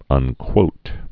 (ŭn-kwōt, ŭnkwōt)